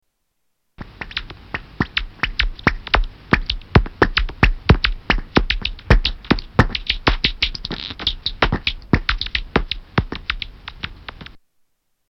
Noctule bat echolocation
Category: Animals/Nature   Right: Personal